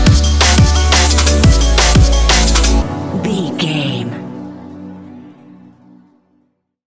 Ionian/Major
B♭
electronic
techno
trance
synths
synthwave
instrumentals